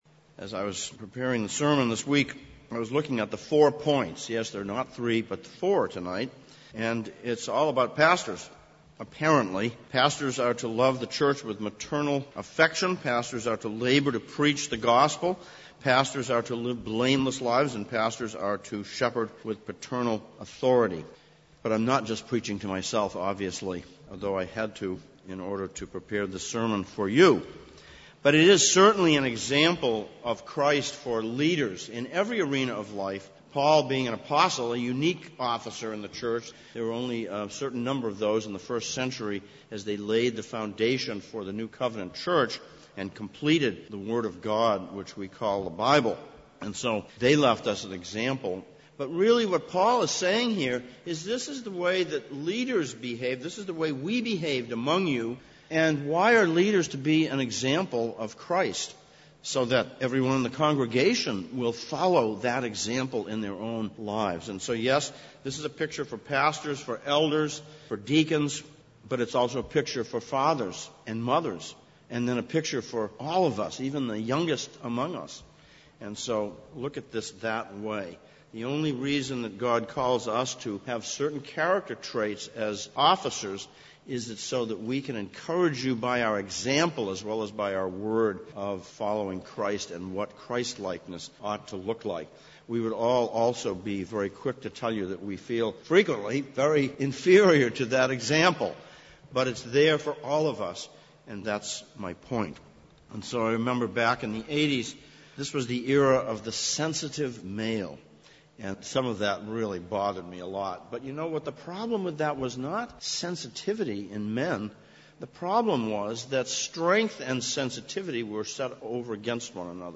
Sermon text: 1 Thessalonians 2:7-12
Service Type: Sunday Evening